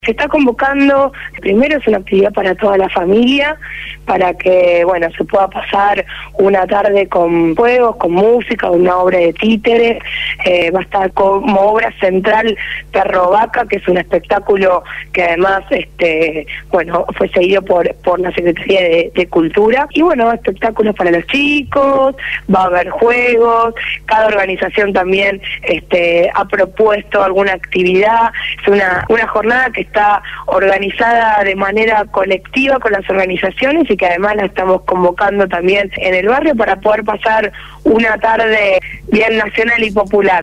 Victoria Colombo, referente de la agrupación La Cámpora y Comunera electa en la Comuna 4 por el Frente Para la Victoria habló en el programa Punto de Partida de Radio Gráfica FM 89.3 con motivo del Festival por el Día del Niño que se realizará a partir de las 14 horas en Iriarte y Vélez Sarfield, en el Barrio de Barracas de la Ciudad de Buenos Aires.